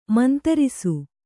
♪ mantarisu